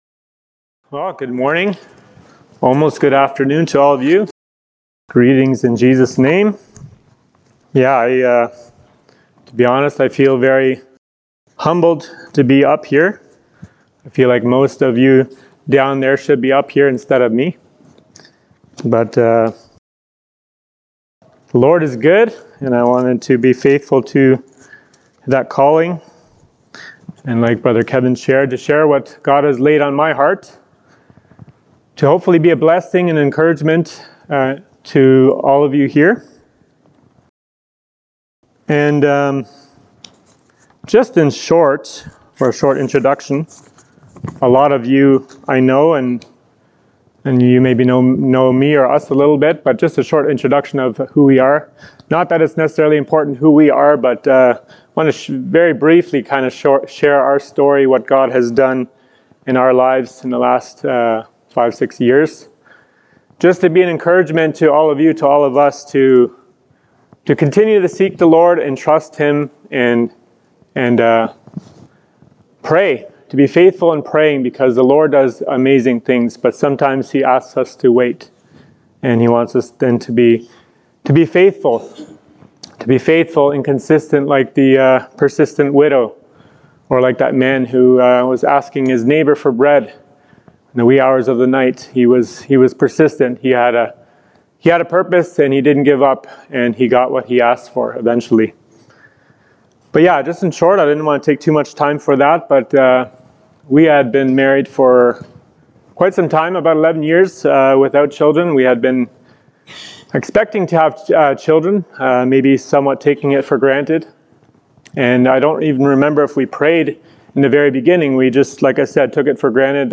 Saturday Morning Men's Message
Service Type: Fellowship Weekend